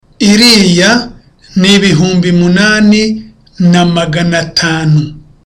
(With a low tone.)